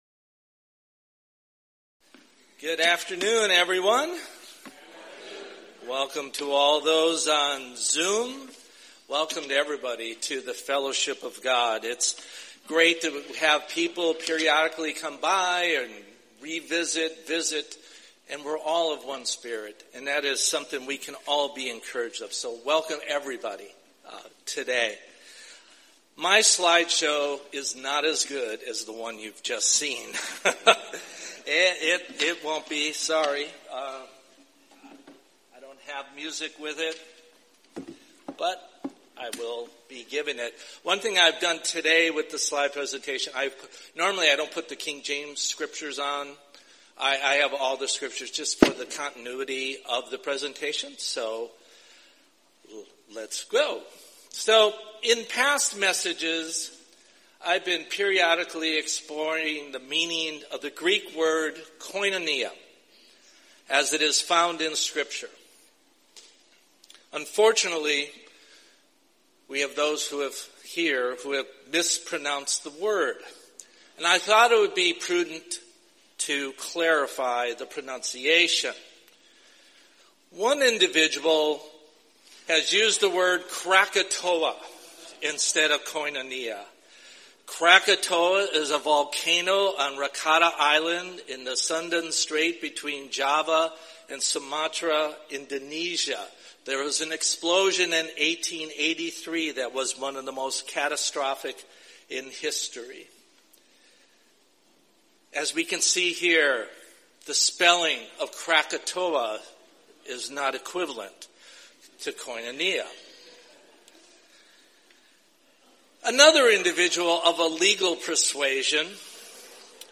Sermon
Given in Bakersfield, CA Los Angeles, CA